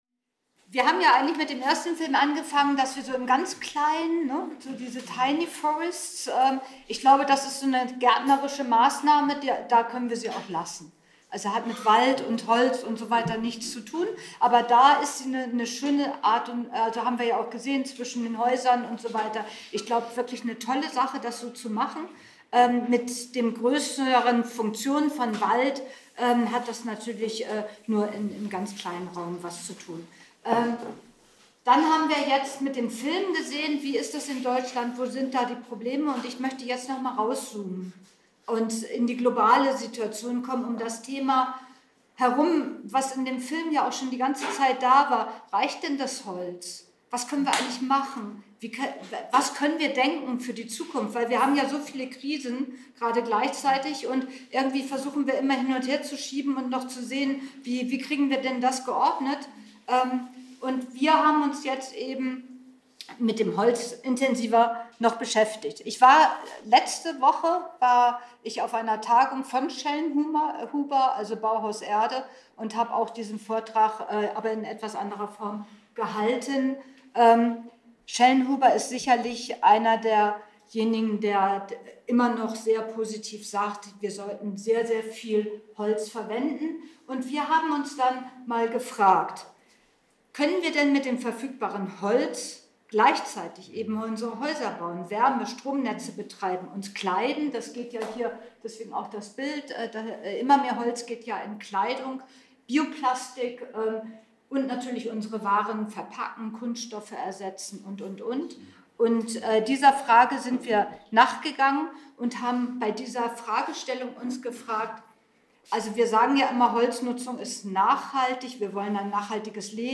Publikumsgespräch